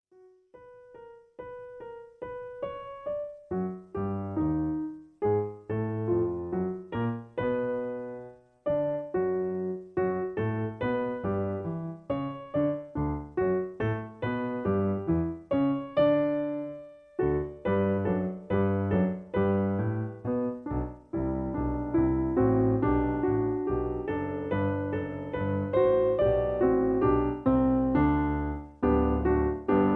In B. Piano Accompaniment